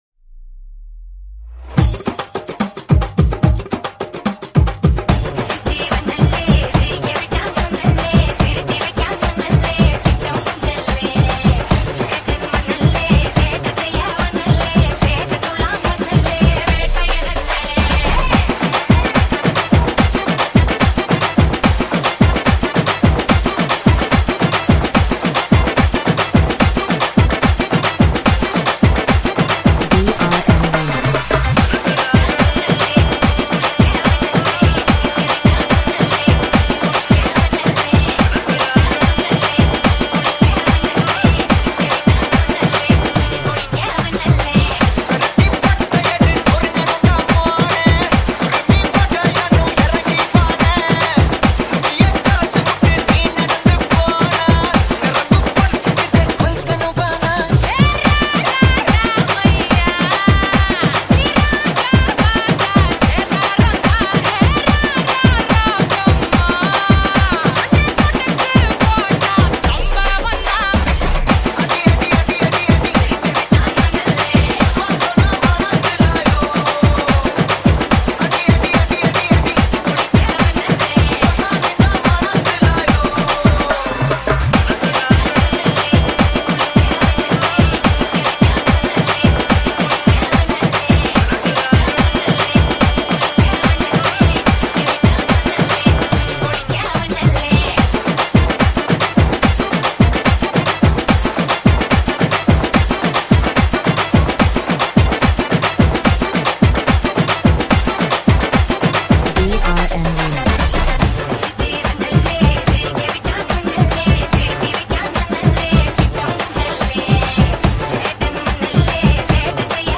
Choka Baila Dance Mix